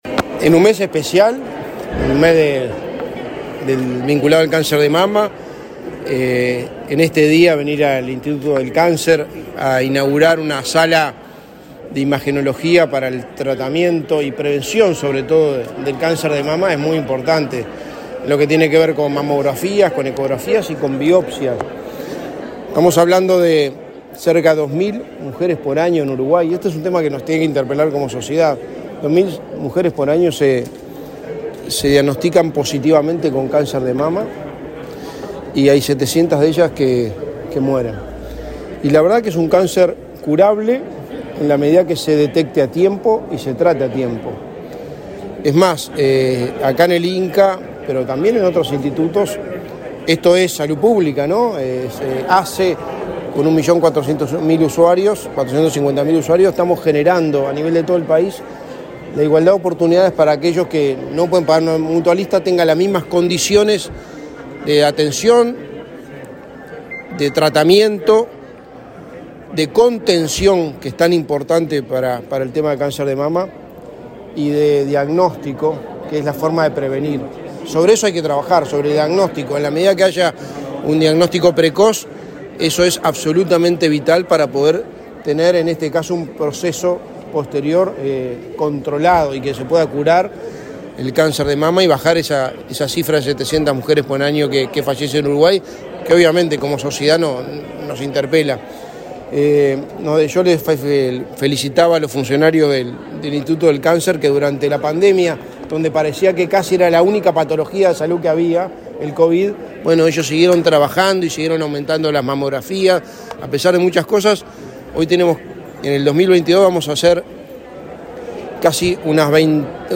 Declaraciones del secretario de Presidencia, Álvaro Delgado
Declaraciones del secretario de Presidencia, Álvaro Delgado 25/10/2022 Compartir Facebook X Copiar enlace WhatsApp LinkedIn El secretario de Presidencia, Álvaro Delgado, dialogó con la prensa luego de participar en la inauguración del Área de Imagenología Mamaria y la Unidad de Estética Oncológica del Instituto Nacional del Cáncer.